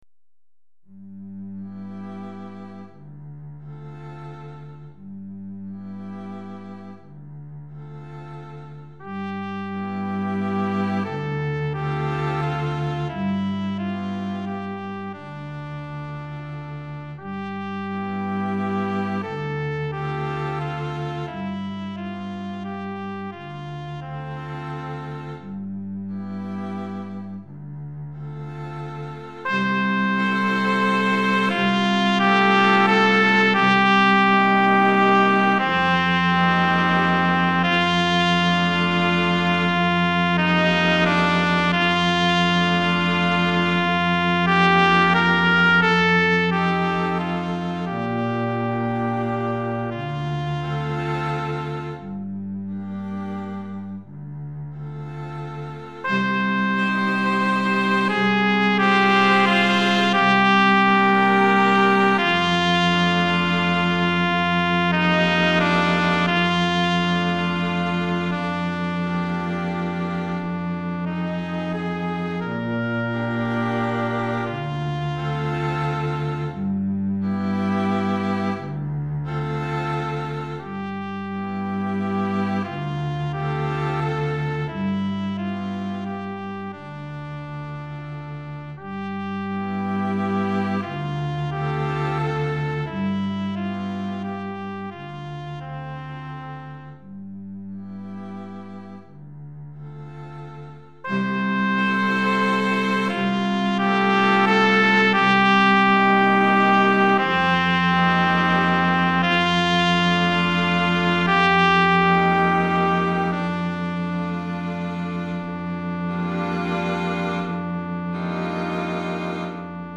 Trompette et Quatuor à Cordes